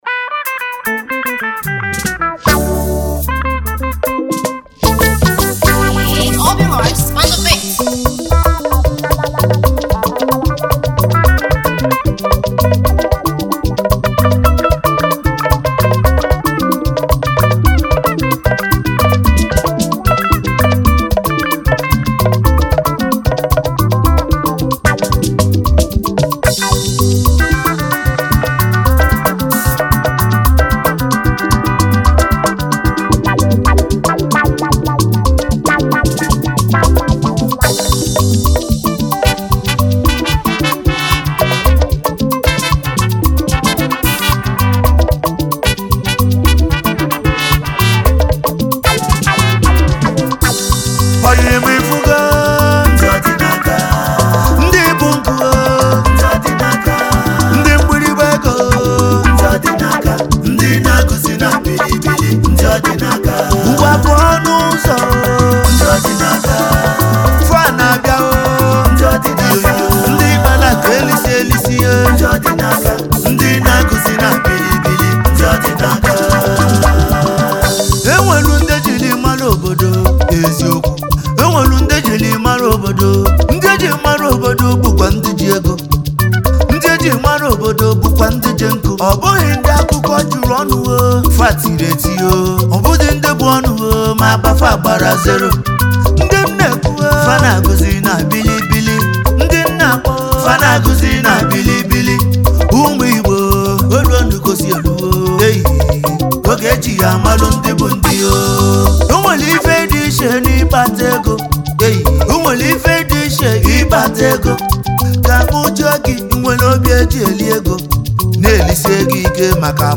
reflective, grounded, and deeply personal